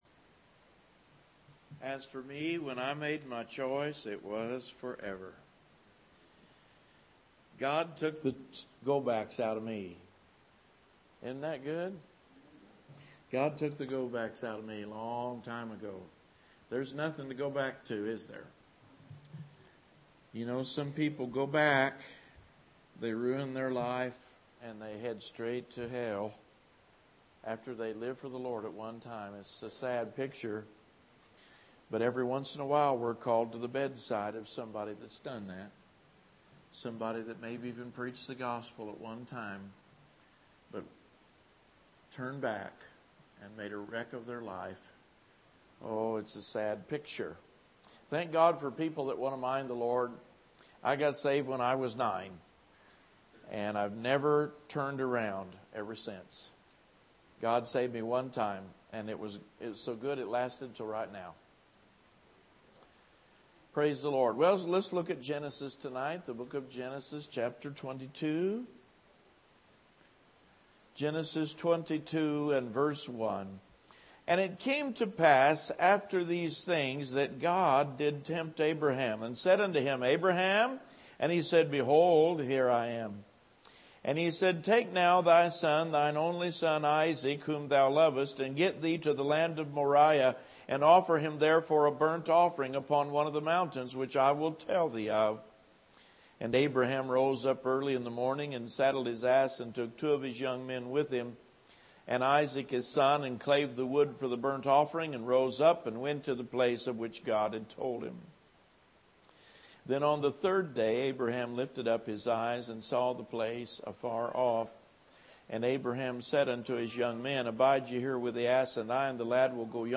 Series: Spring Revival 2015 Tagged with Abraham , altar , Isaac , testing